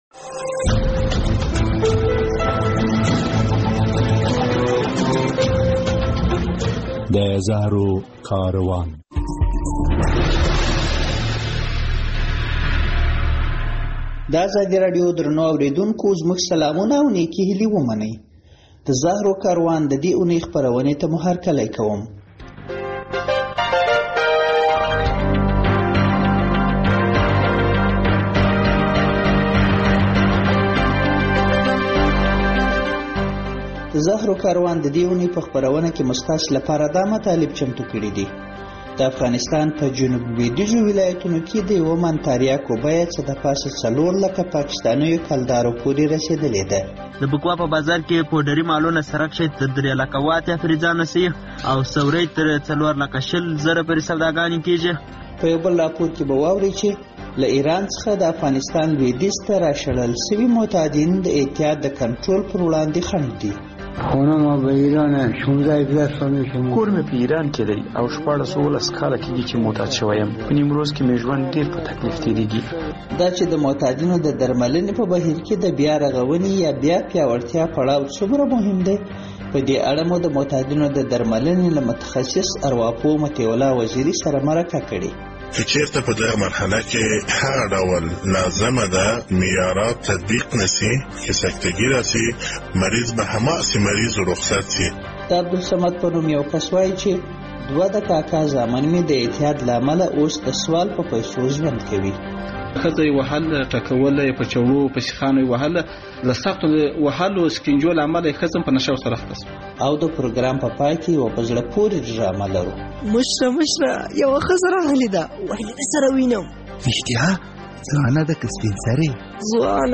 د زهرو کاروان په دې خپرونه اورئ چې د افغانستان په جنوب لوېدیځ کې د تاریاکو بیه د پام وړ لوړه شوې ده. له نیمروز ولایت څخه به په یوه راپور کې واورئ چې له ایرانه راشړل شویو معتادینو په دغه ولایت کې د معتادینو د درملنې چارې ټکنۍ کړي.